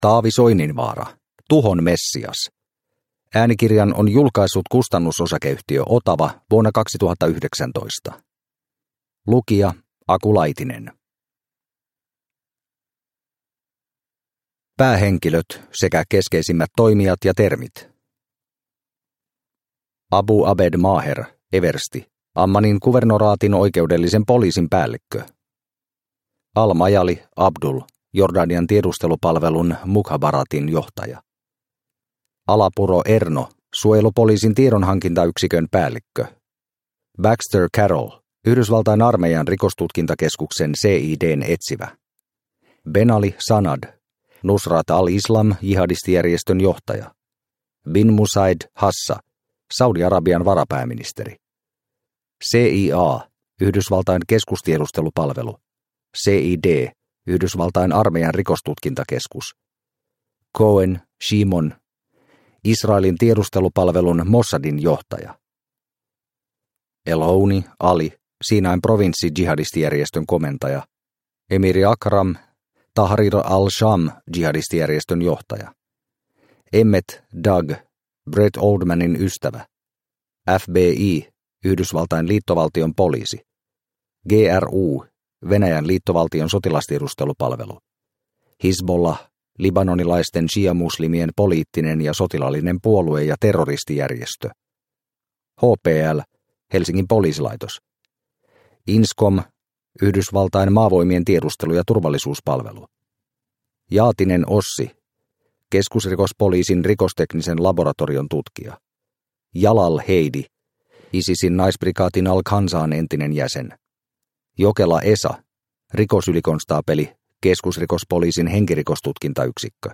Tuhon messias – Ljudbok – Laddas ner